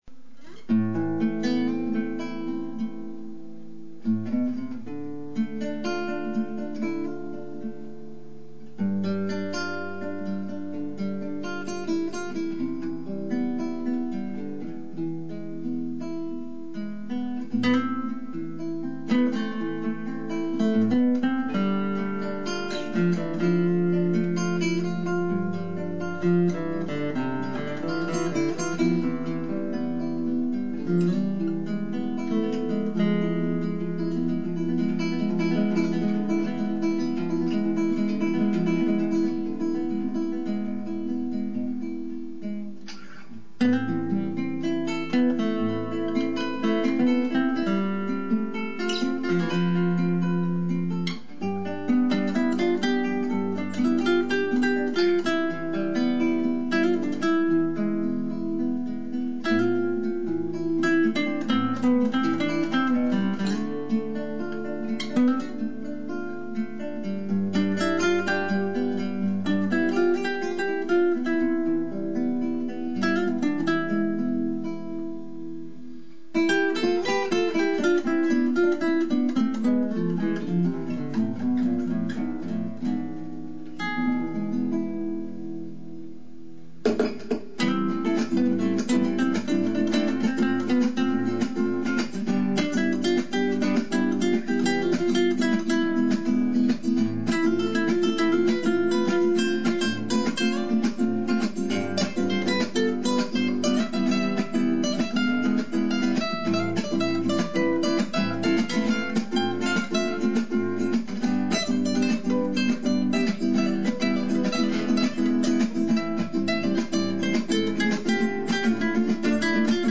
Ein bißchen Spanien in Köln.